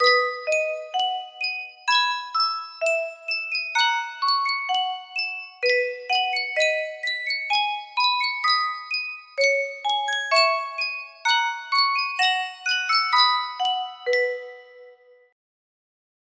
Yunsheng Music Box - Mozart Piano Concerto No. 23 5798 music box melody
Full range 60